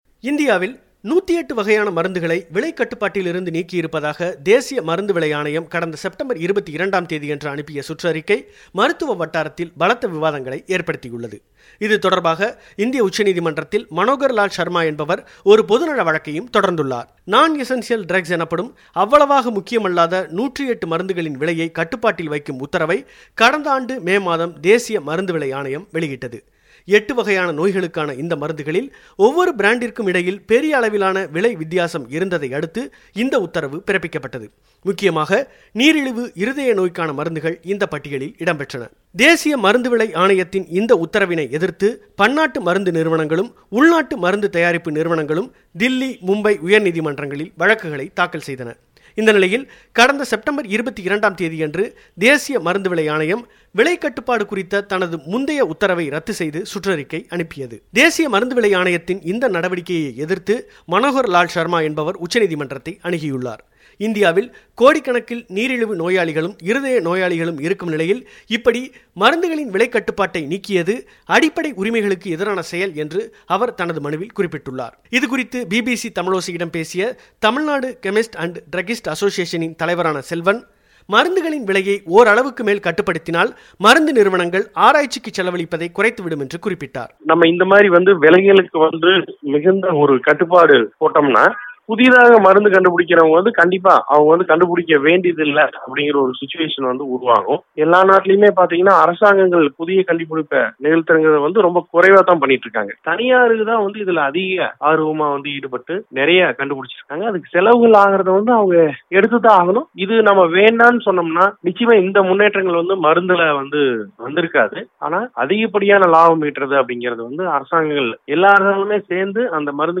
பெட்டகம்.